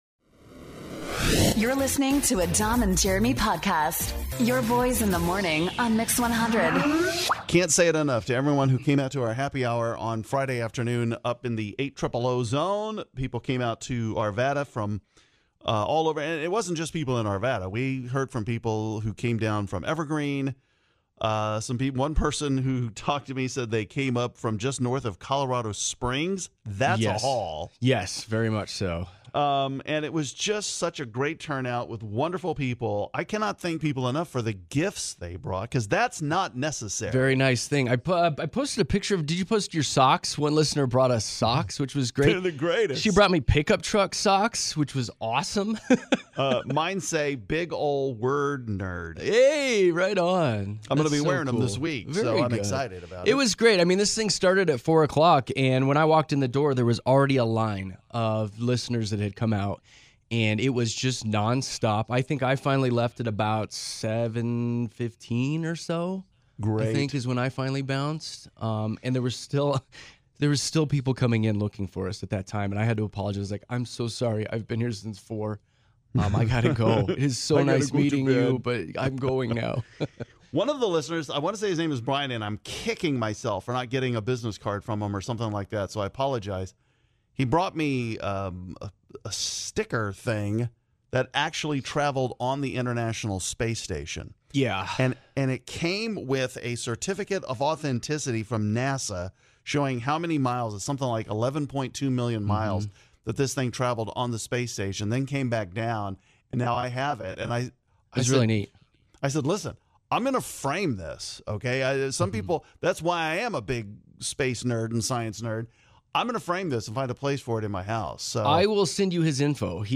We had a BLAST at the get together in Arvada with all of you!